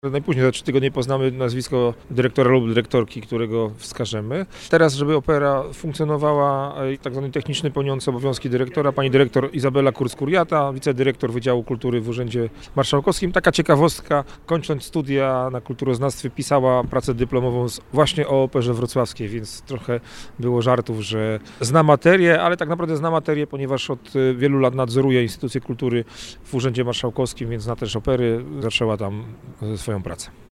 Mówi Krzysztof Maj – Członek Zarządu Województwa Dolnośląskiego.